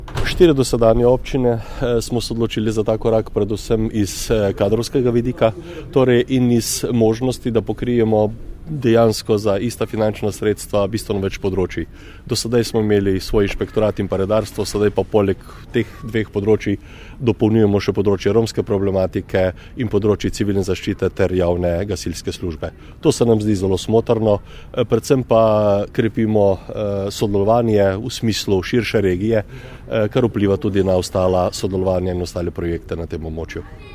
Šentjernejski župan Jože Simončič o tem, zakaj so se tri nove občine pridružile skupni občinski upravi občin Dolenjske in Bele krajine